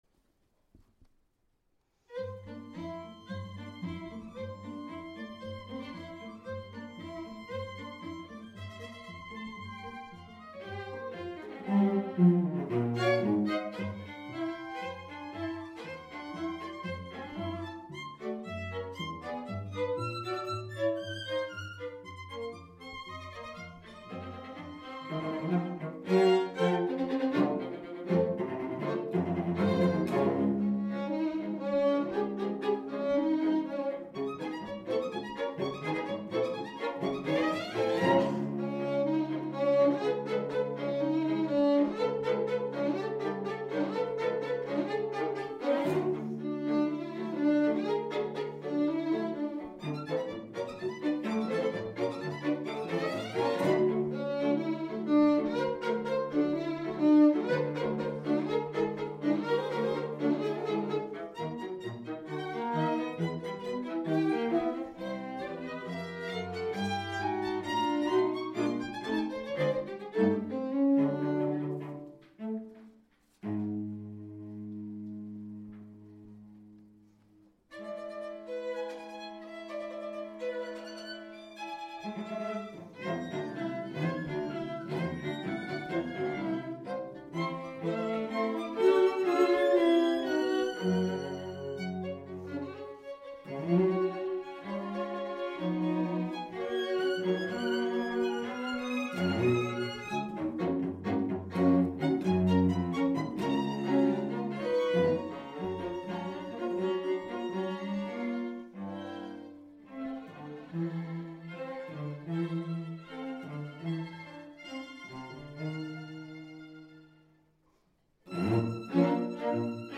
für Streichquartett